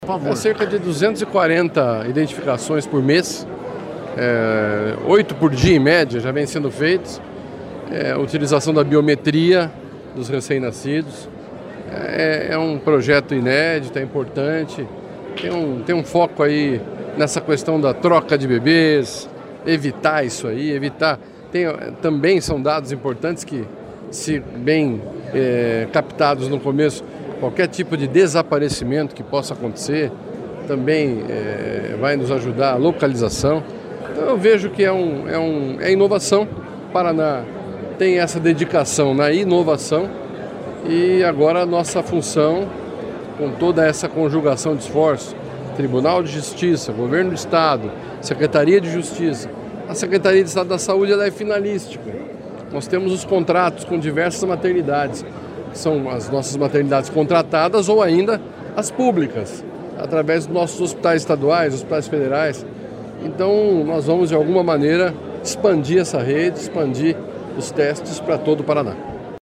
Sonora do secretário Estadual da Saúde, Beto Preto, sobre o projeto de biometria neonatal Bebê ID